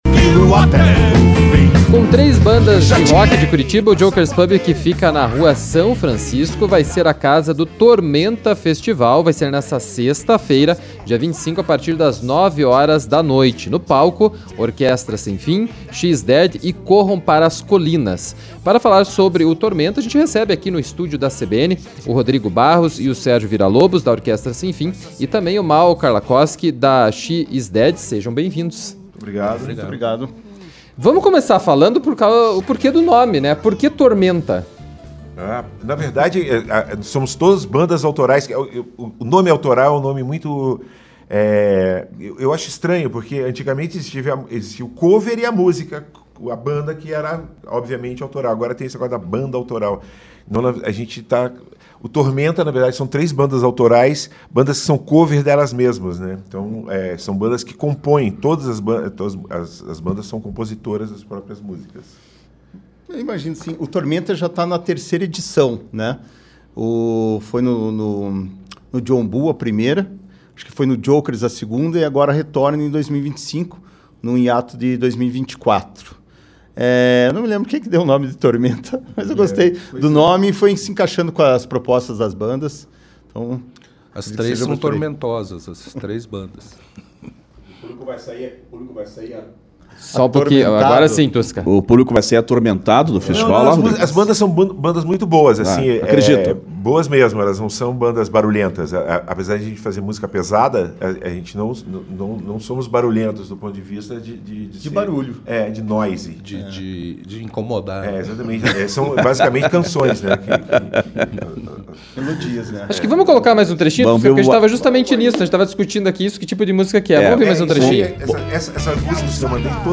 Para falar sobre as apresentações desta sexta (25), integrantes da Orquestra Sem Fim e do She Is Dead participaram da Quarta Cultural da CBN.